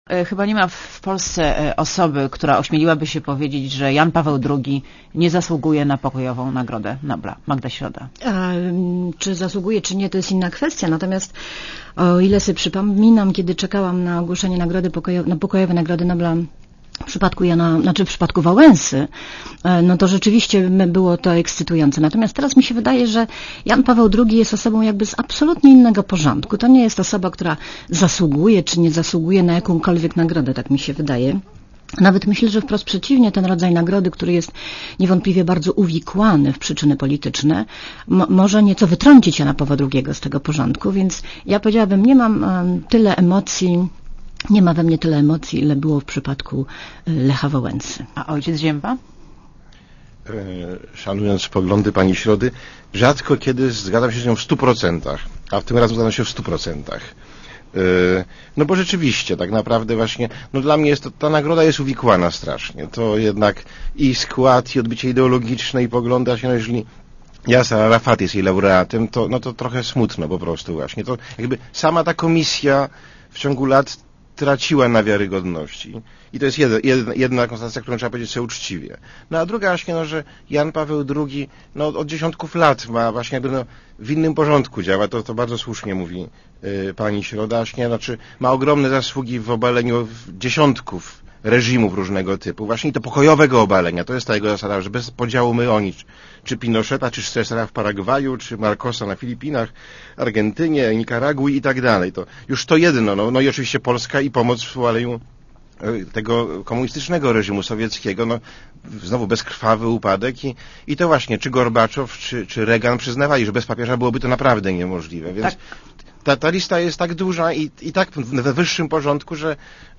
© (RadioZet) Posłuchaj wywiadu (2,9 MB) Chyba nie ma w Polsce osoby, która ośmieliłaby się powiedzieć, że Jan Paweł II nie zasługuje na pokojową Nagrodę Nobla.